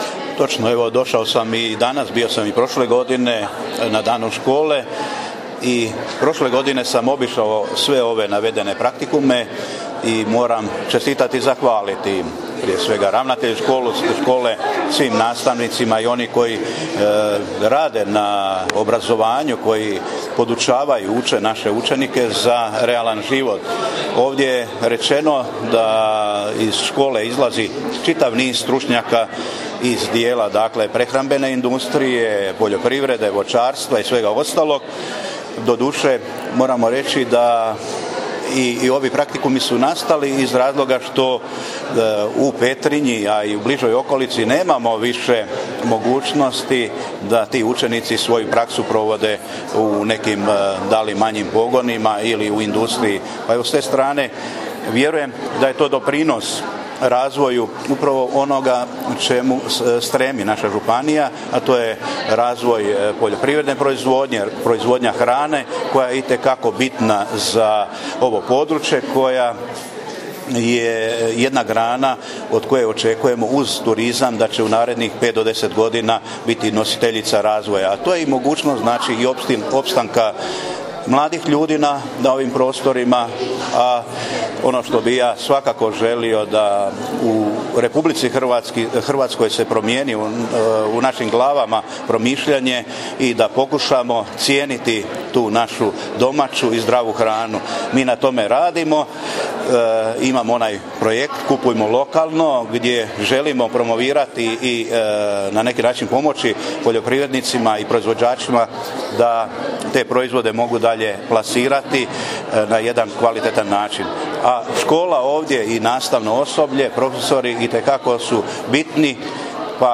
Dan Srednje škole Petrinja
Poslušajte izjavu koju je u ovoj prigodi dao župan Ivo Žinić: